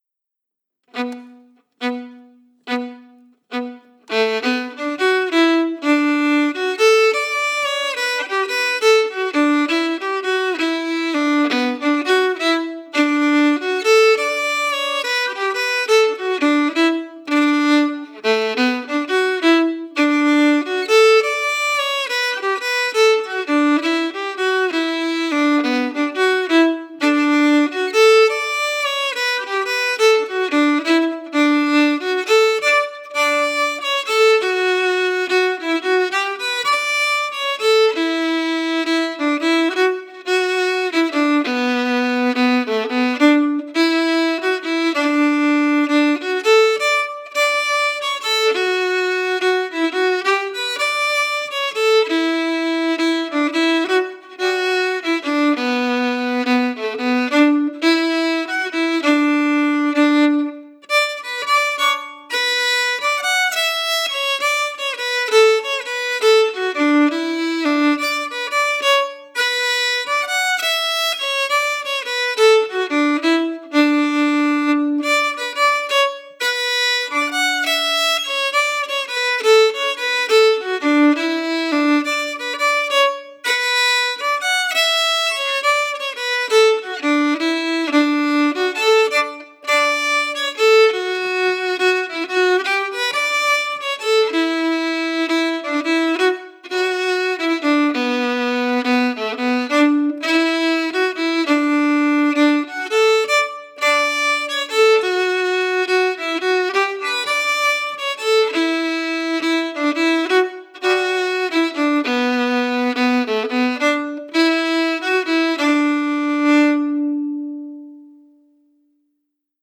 Key: Bm
Form: Jig
Played slowly for learning
Region: Shetland